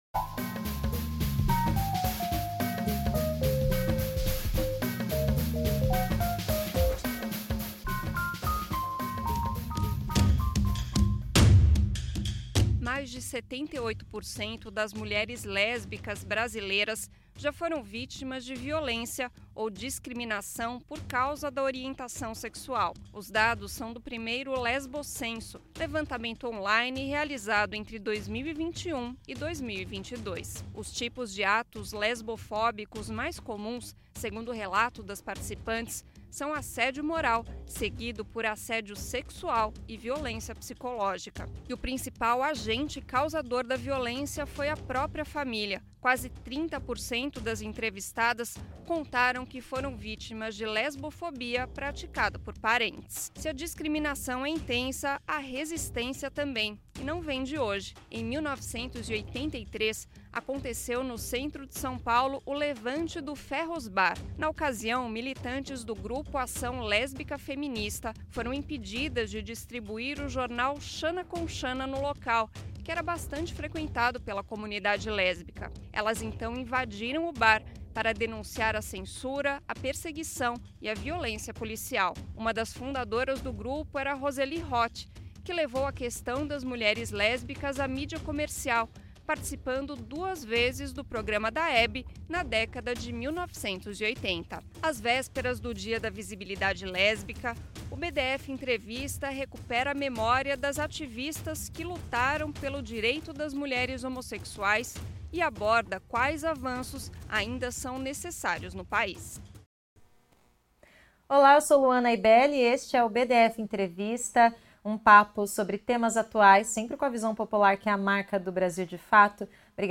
feita durante o BdF Entrevista